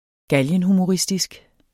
Udtale [ ˈgaljən- ]